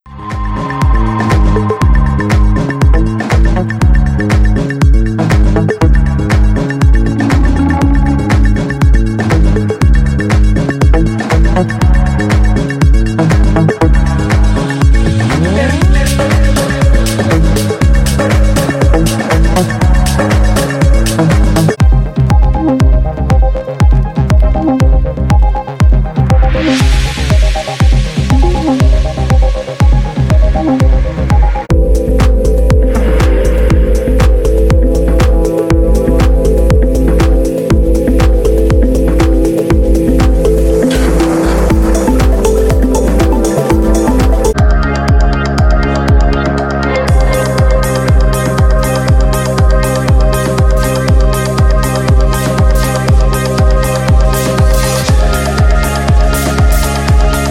这就是为什么我们准备了一系列新鲜效果，准备在Organic House，Deep House，Afro House，Melodic House & Techno，Progressive House，Minimal，Downtempo，Electronica，Techno，Tech House或任何其他类型中使用。
• 130 x Sfx （格式： WAV 44.1 kHz， 24 位）